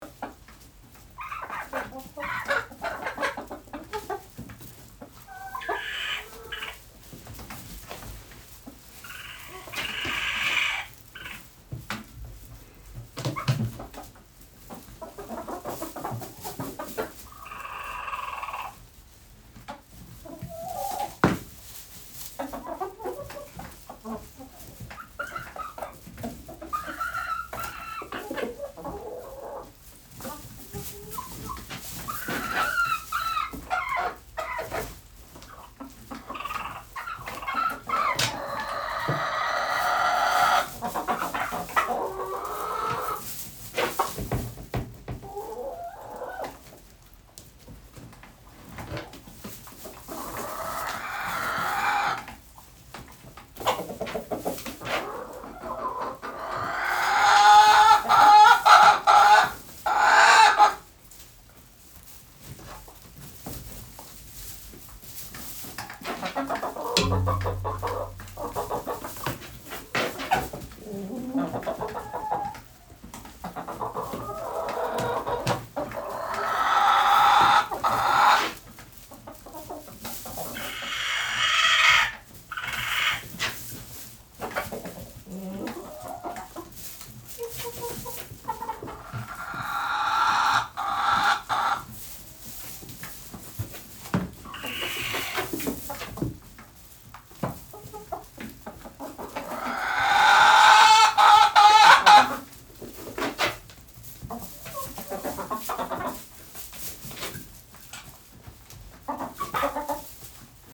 Gallina Brahma (Gallus gallus domesticus)